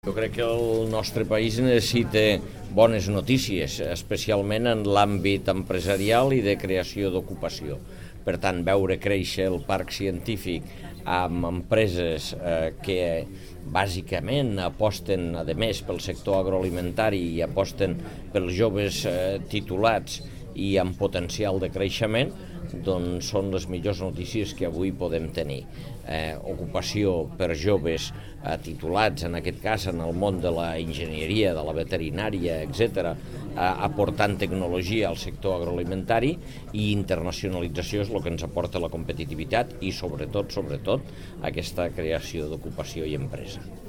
Tall de veu de l'alcalde, Àngel Ros, sobre la instal·lació d'Agrifood al Parc Científic i Tencològic (685.7 KB) Fotografia 1 amb major resolució (505.9 KB) Fotografia 2 amb major resolució (840.2 KB) Fotografia 3 amb major resolució (448.4 KB)
tall-de-veu-de-lalcalde-angel-ros-sobre-la-instal-lacio-dagrifood-al-parc-cientific-i-tencologic